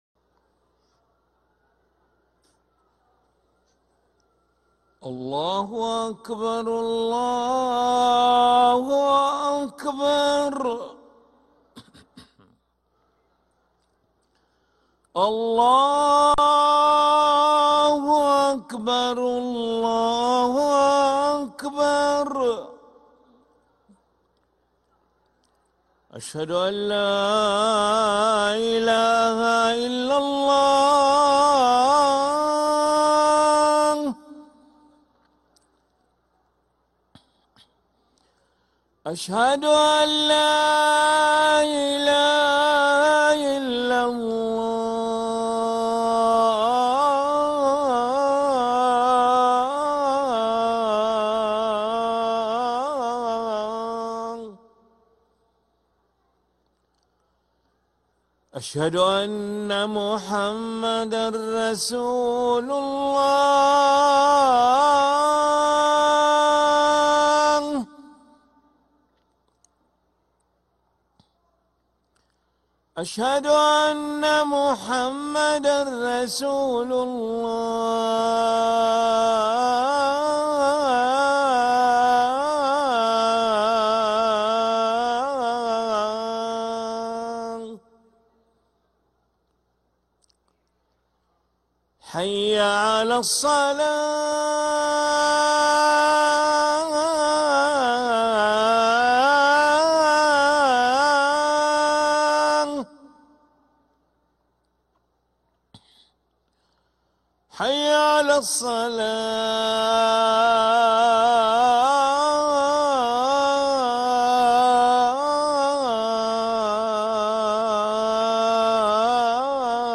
أذان العشاء للمؤذن علي ملا الخميس 7 ربيع الثاني 1446هـ > ١٤٤٦ 🕋 > ركن الأذان 🕋 > المزيد - تلاوات الحرمين